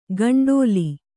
♪ gaṇḍōli